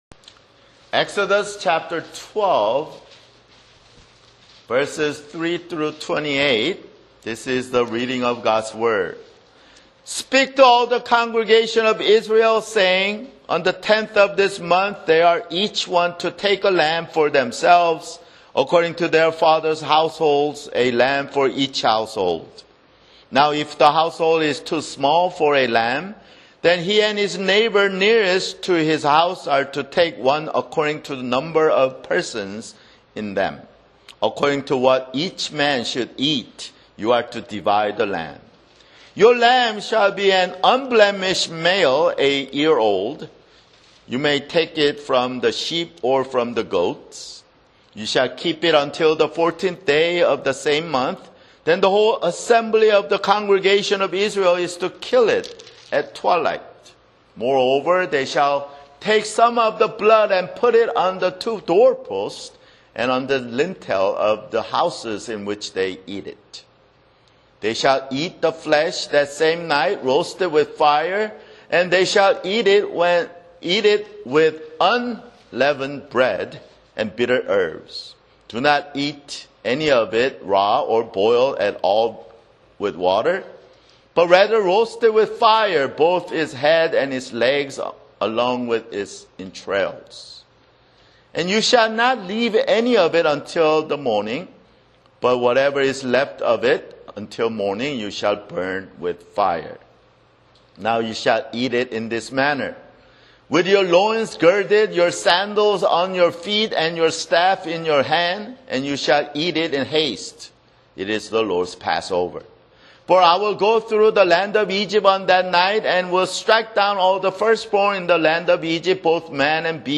[Sermon] Exodus (27)